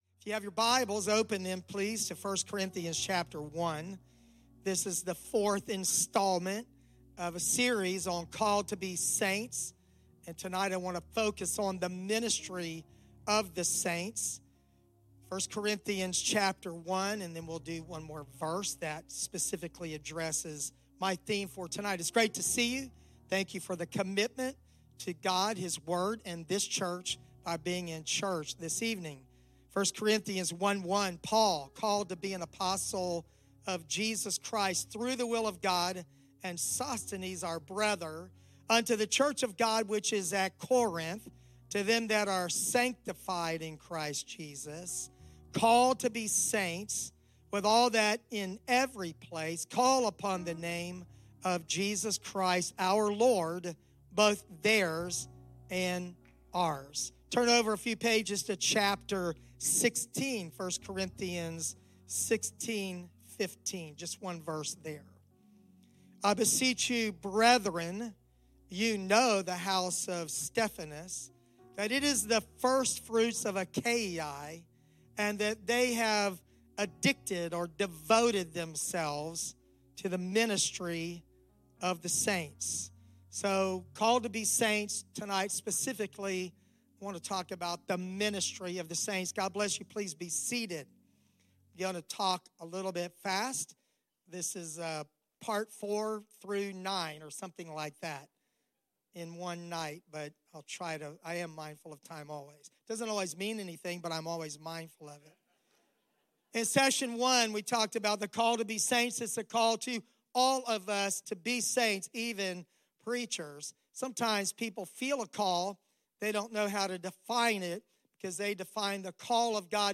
Messages from our midweek worship service at Atlanta West Pentecostal Church.